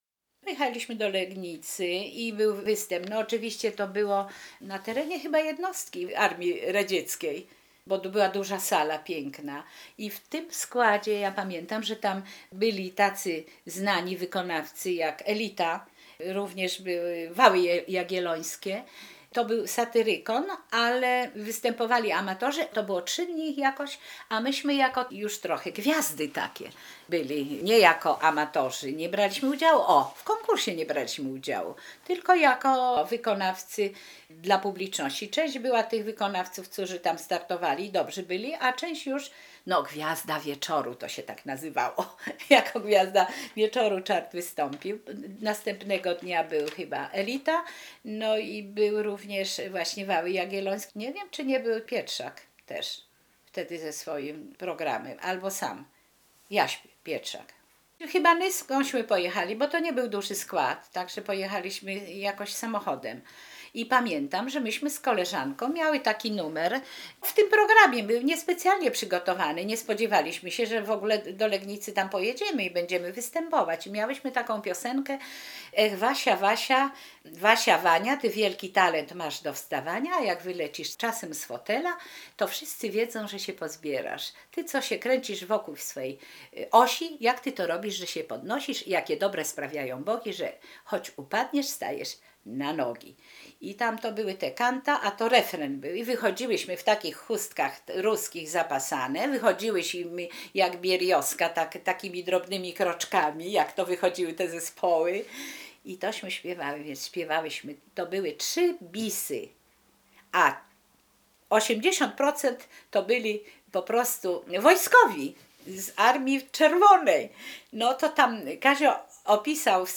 Relacja mówiona zarejestrowana w ramach Programu Historia Mówiona realizowanego w Ośrodku